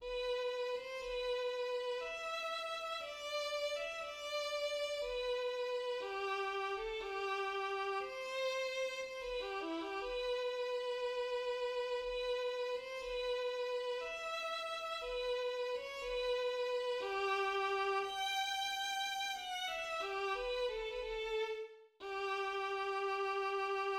Theme (E minor)